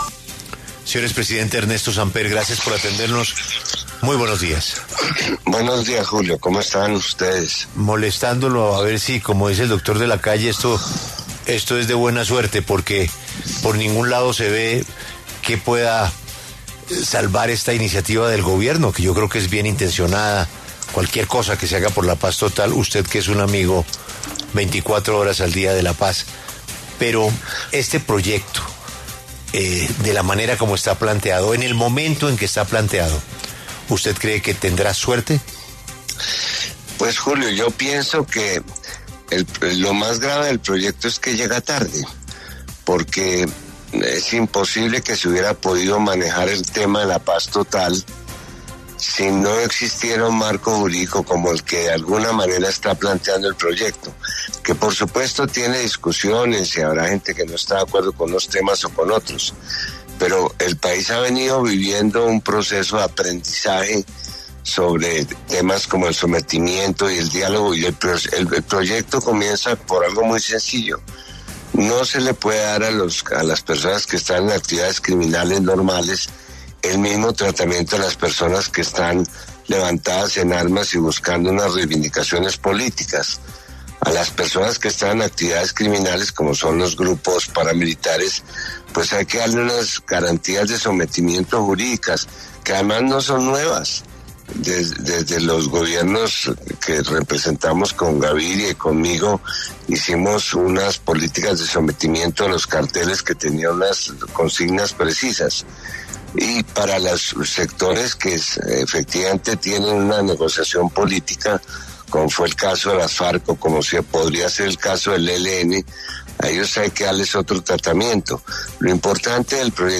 El expresidente Ernesto Samper conversó con La W sobre el proyecto de paz total del Gobierno de Gustavo Petro, ya radicado ante el Congreso por el Minsiterio de Justicia.